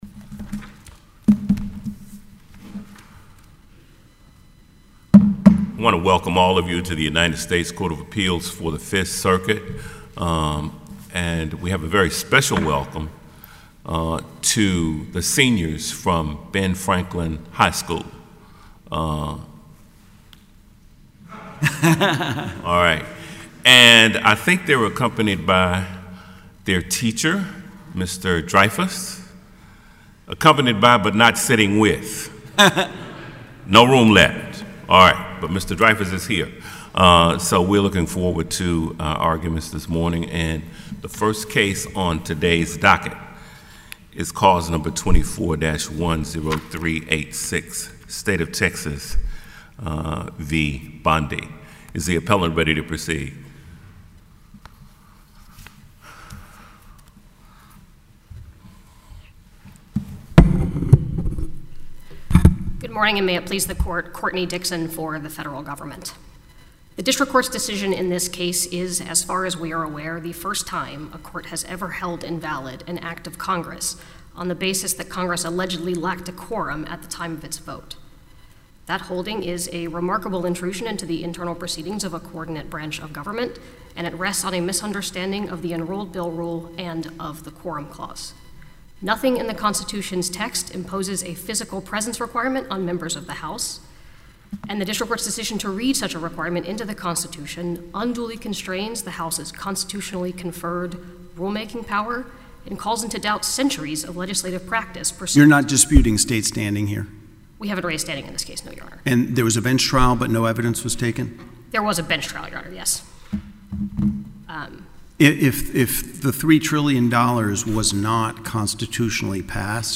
In the United States’ appeal, now captioned Texas v. Bondi, a panel of the Court of Appeals for the Fifth Circuit (Judges Graves, Higginson, and Wilson) heard oral argument on February 25, 2025.